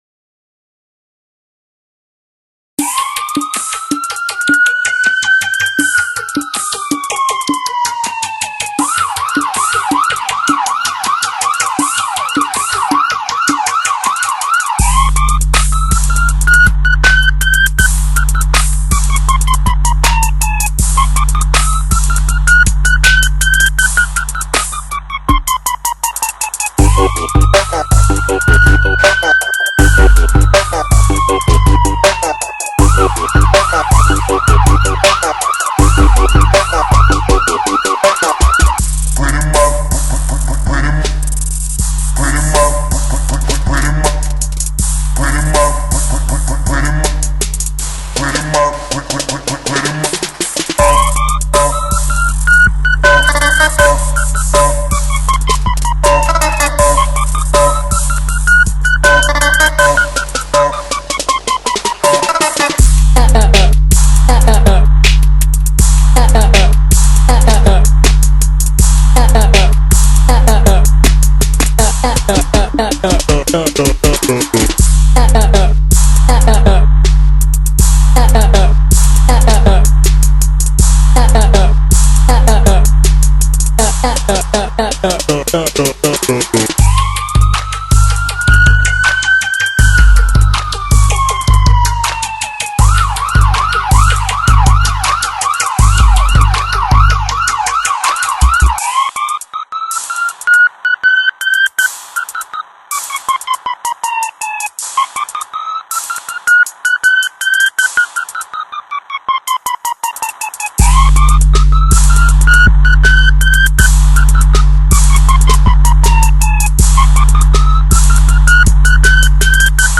آهنگ پلیسی شوتی جدید خارجی شوتی بازان حرفه ای بیس کوبنده